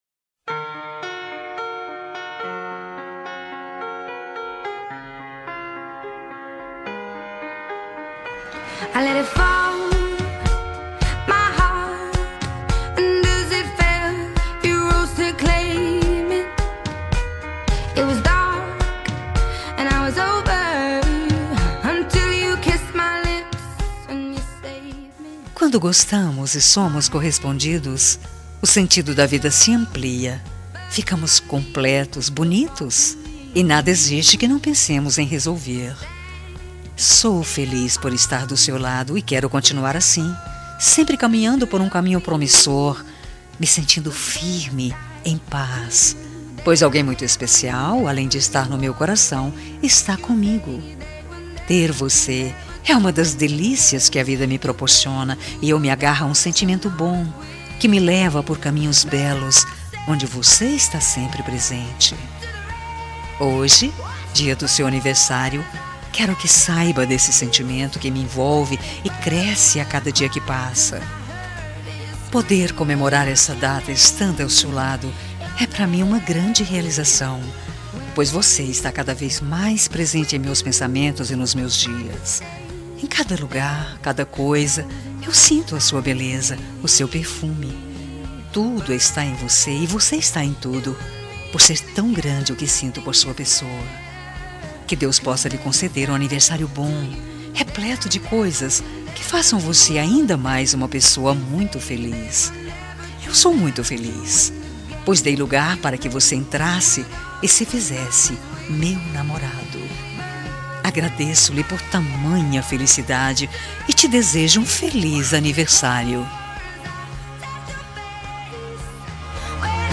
Telemensagem Aniversário Romântico – Voz Feminina – Cód: 09811
aniversario-romantico-suave-02-f-adele-set-fire-to-the-rain.mp3